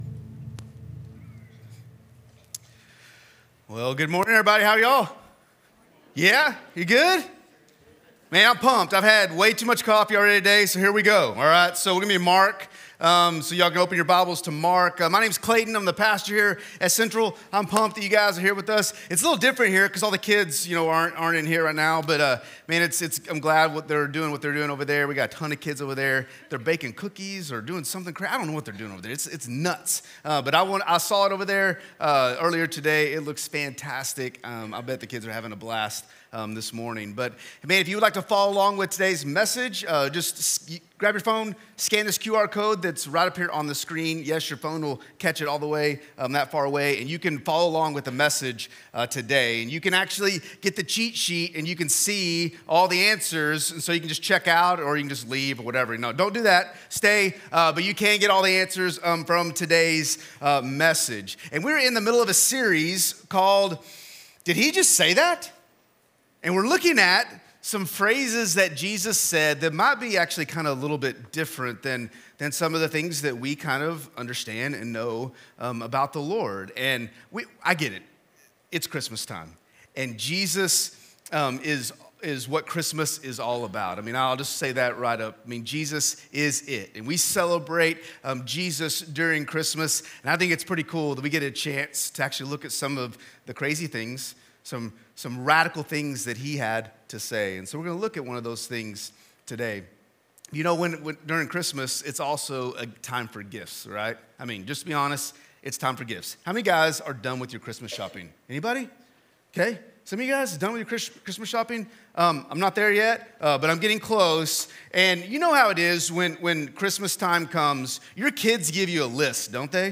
A message from the series "Did He Just Say That?."